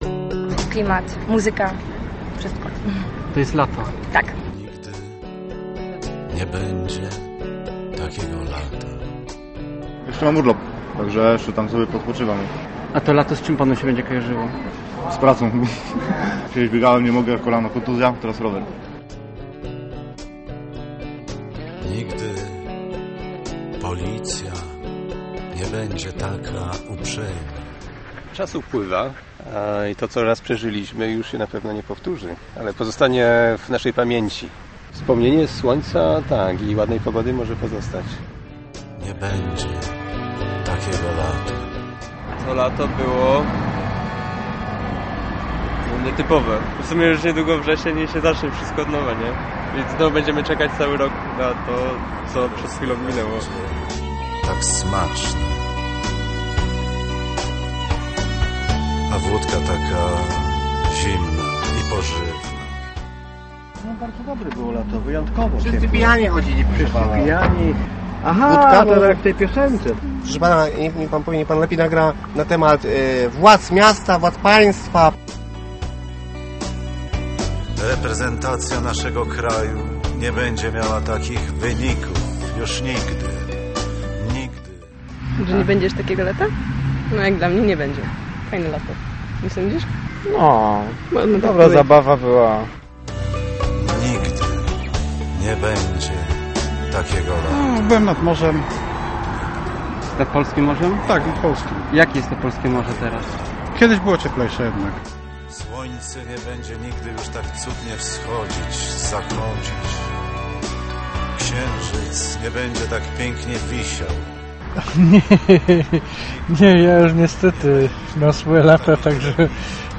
Czy to prawda - sprawdzaliśmy na poznańskich ulicach, w parkach i na dworcu.